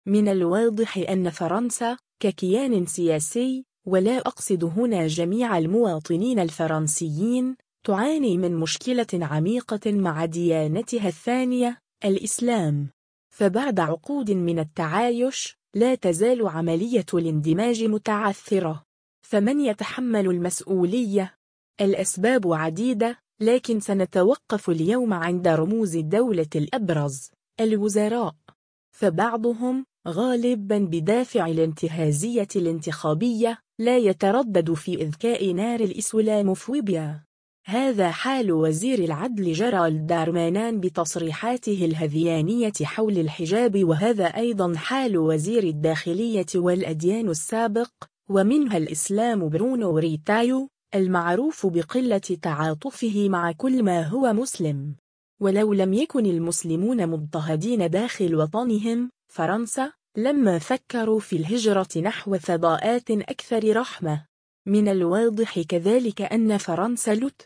وهذا النائب من “فرنسا الأبيّة” (LFI) يقدّم نموذجًا على ذلك. ففي فيديو بعنوان “رسالة إلى مواطناتي ومواطنَي المسلمين”، قال أنطوان لياومان، نائب الدائرة العاشرة في إيسون، ما يلي: